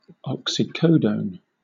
Ääntäminen
Southern England RP : IPA : /ˌɒk.sɪˈkəʊ.dəʊn/